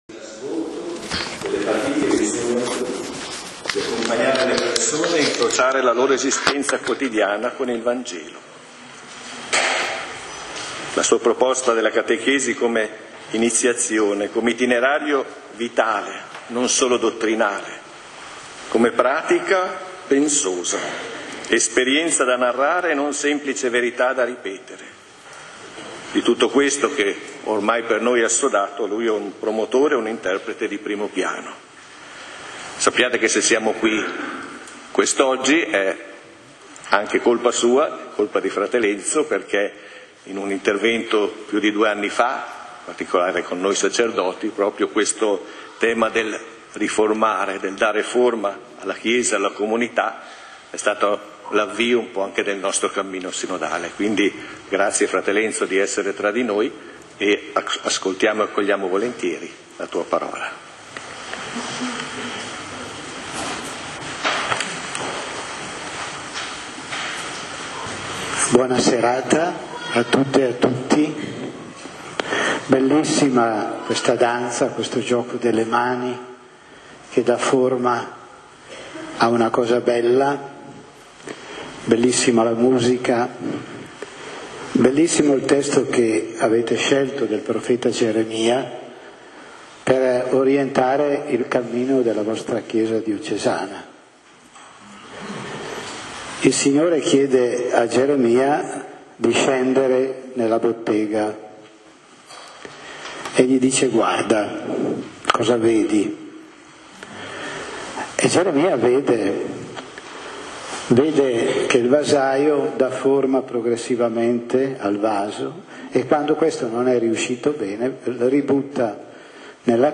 E' stata aperta domenica 10 settembre a S. Giorgio con una folta partecipazione di sorelle e fratelli, suore e preti provenienti dalle diverse comunità della diocesi. Il tema è: " FINCHE' CRISTO SIA FORMATO IN VOI " Questa l'icona con la relativa presentazione per la nostra comprensione-lettura.